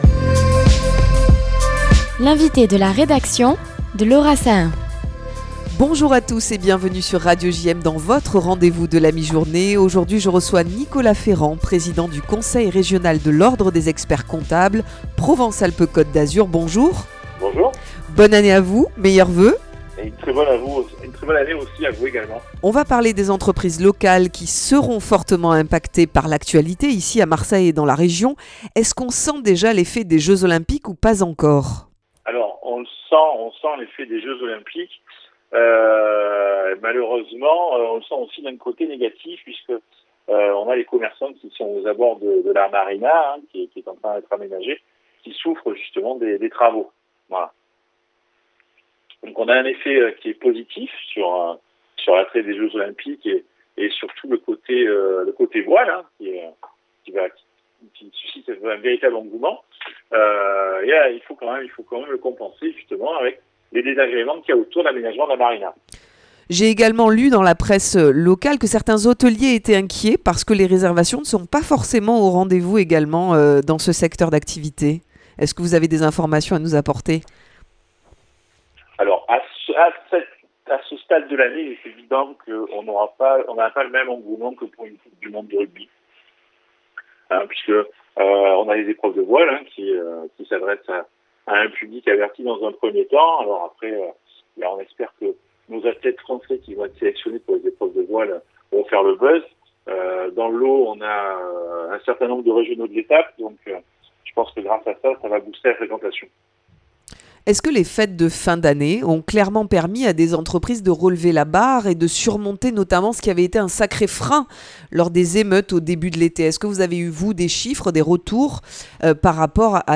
3. L'invité de la rédaction